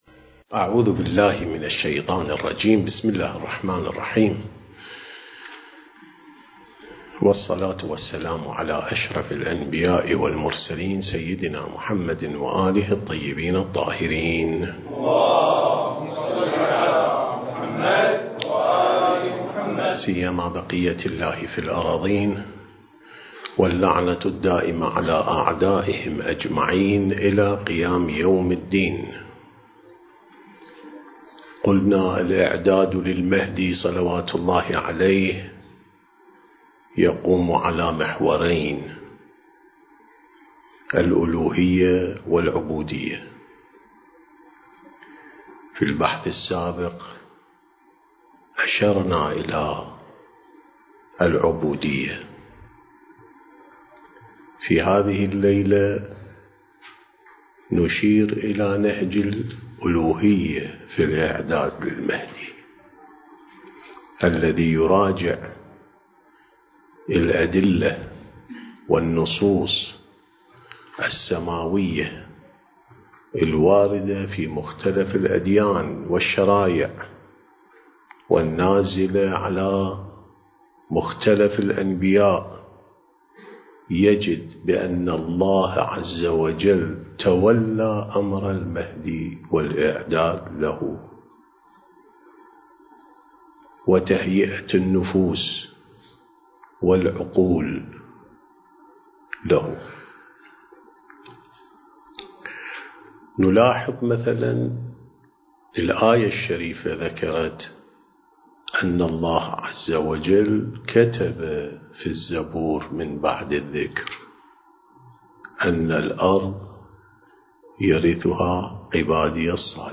سلسلة محاضرات: الإعداد الربّاني للغيبة والظهور (4)